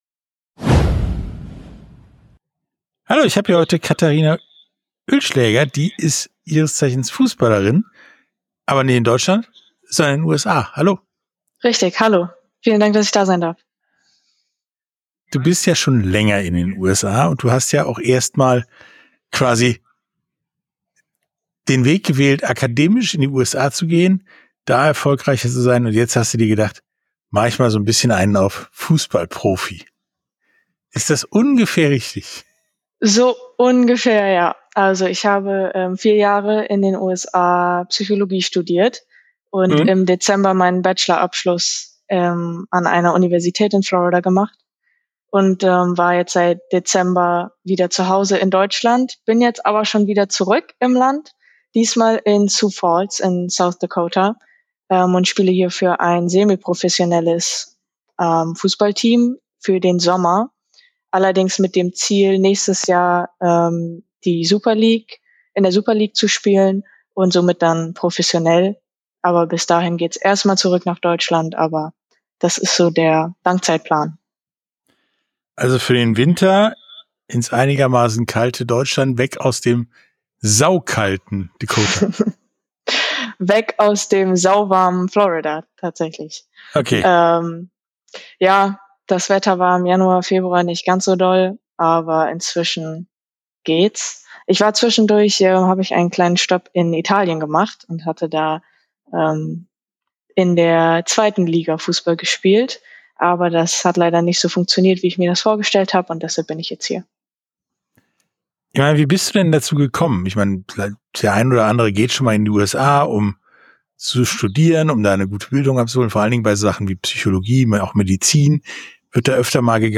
Sportstunde - Interview